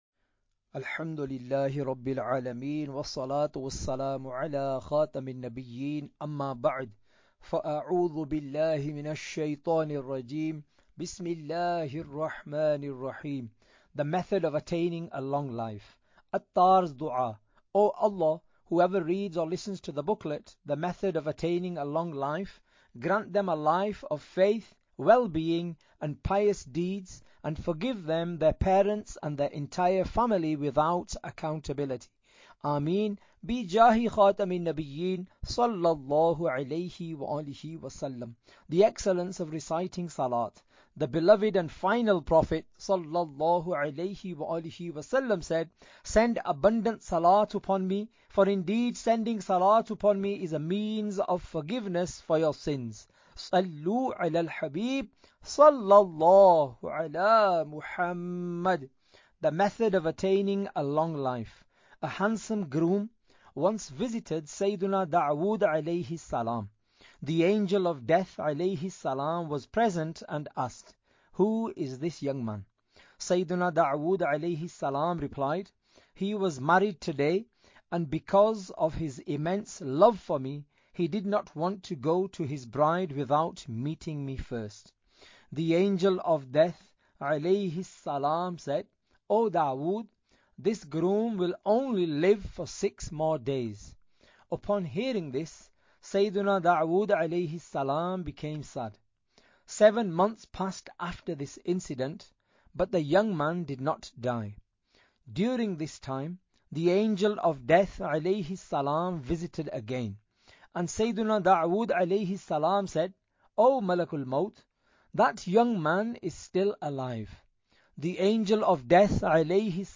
Audiobook - The Method of Attaining a Long Life (English)